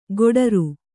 ♪ goḍaru